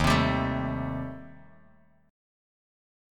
D#6b5 chord